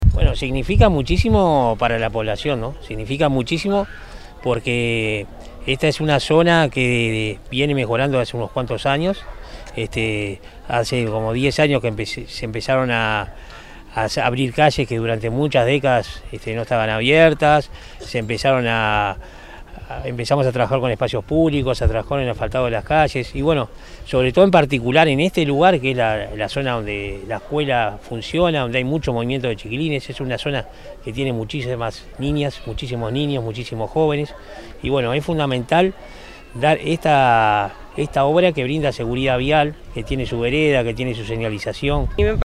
El miércoles 12 de abril en Colonia Nicolich se llevó a cabo la inauguración de obras de pavimentación. La ceremonia se realizó frente a la escuela N° 155 y participó el Secretario General de la Intendencia de Canelones, Dr. Esc. Francisco Legnani, el Alcalde del Municipio de Nicolich – Ciudad Gral. Líber Seregni, Líber Moreno, entre otras autoridades, vecinas y vecinos de la localidad.